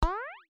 Cute pop4.wav